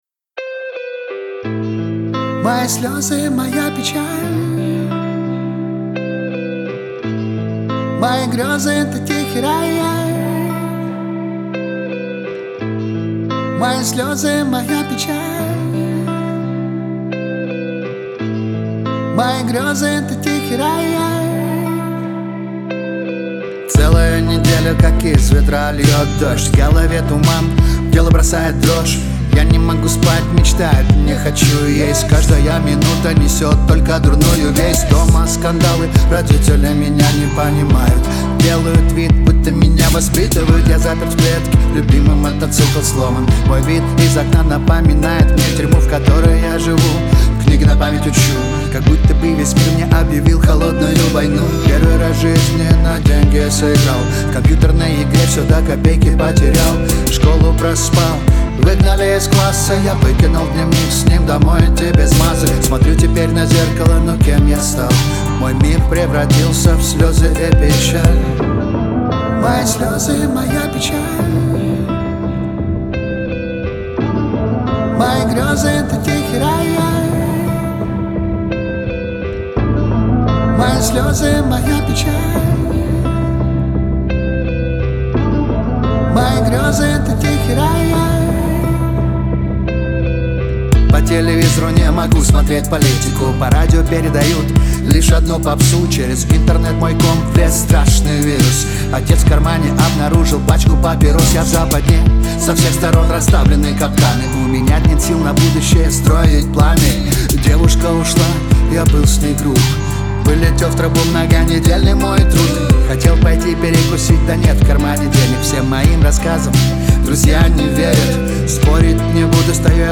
это эмоциональный поп-трек с элементами R&B
мощный вокал